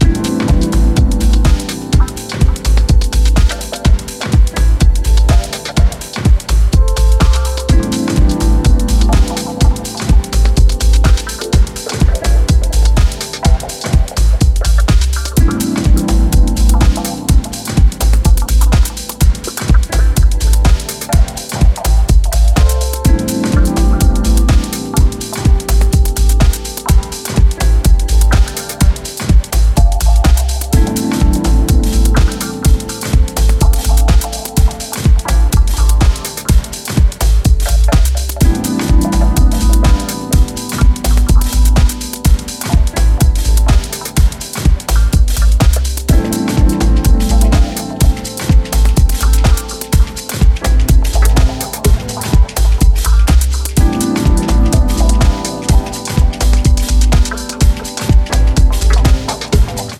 原曲を尊重しながら美麗サイケデリアを醸すディープ・ハウス